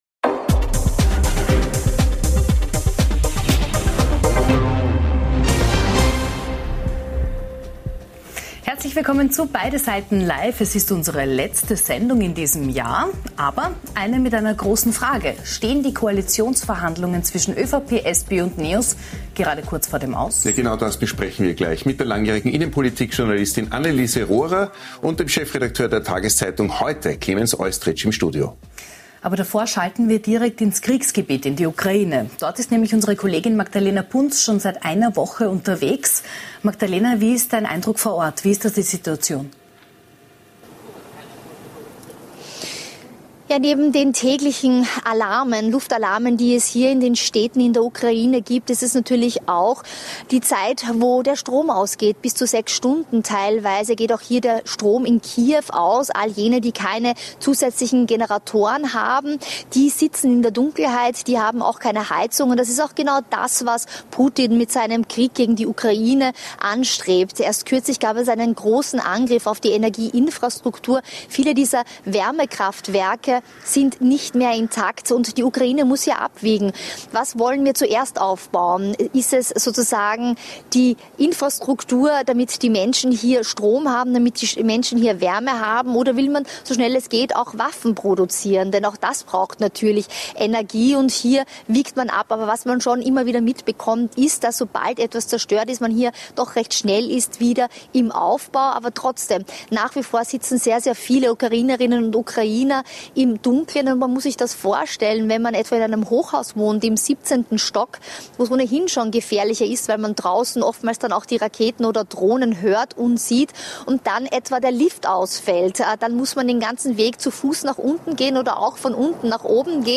Beschreibung vor 1 Jahr Wir beleuchten heute beide Seiten zu folgenden Themen: EU und NATO beraten zum Krieg in der Ukraine - Ist ein Ende des Krieges in Sicht? Die Grünen fliegen aus dem Bundesrat - Ist die Ära der Ökopartei zu Ende? Nachgefragt haben wir heute beim Präsident des Fiskalrates, Christoph Badelt, wie schlecht es denn wirklich um Österreichs Budget steht.